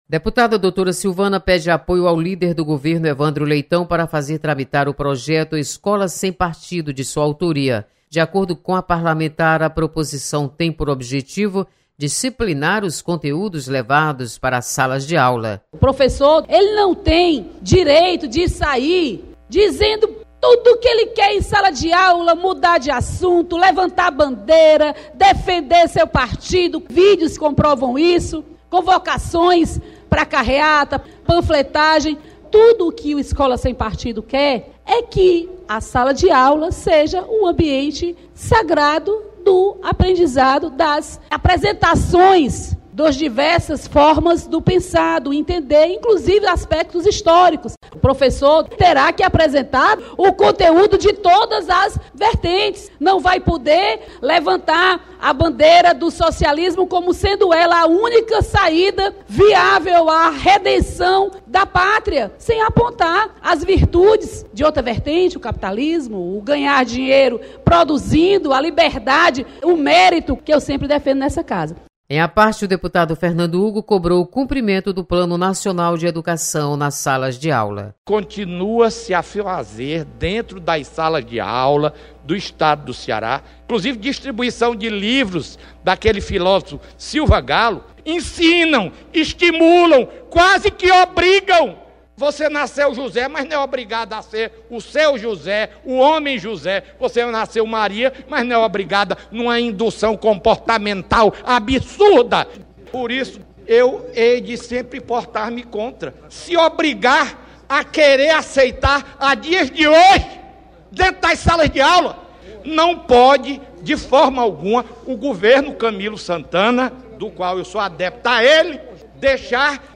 Deputada Doutora Silvana defende projeto Escola sem Partido. Repórter